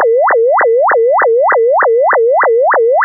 (Fig. 5b): The aliased signal when the receiver sampling frequency was set to 4 kHz.